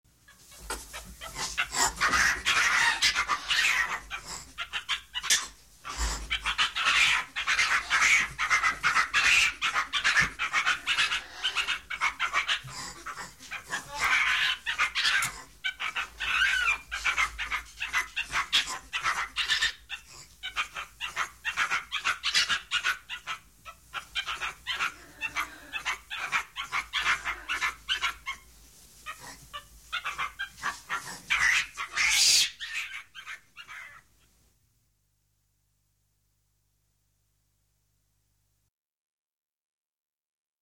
Pianeta Gratis - Audio - Animali
scimmie_monkey03.mp3